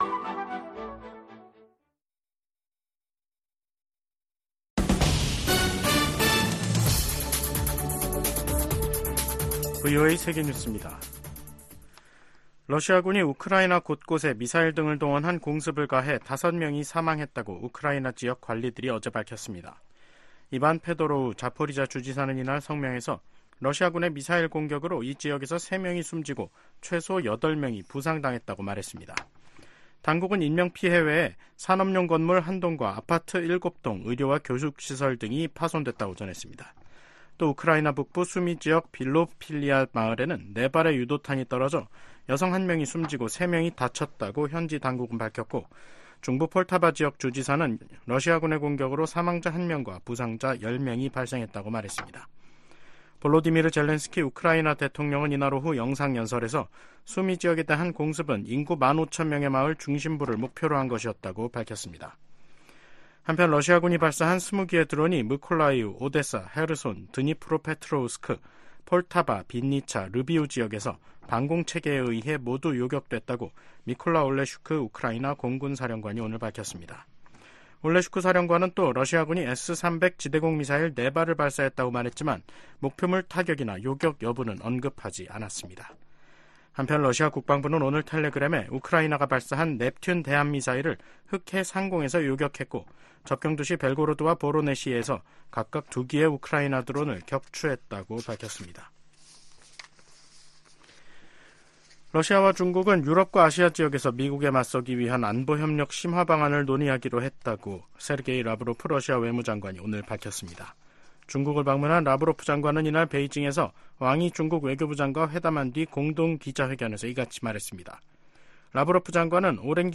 VOA 한국어 간판 뉴스 프로그램 '뉴스 투데이', 2024년 4월 9일 3부 방송입니다. 10일 백악관에서 열리는 미일 정상회담이 두 나라 관계의 새 시대를 여는 첫 장이 될 것이라고 주일 미국대사가 말했습니다. 미국 하원의 일본계 중진의원은 미일 동맹이 역대 최고 수준이라며 이번 주 미일 정상회담에 대한 큰 기대를 나타냈습니다.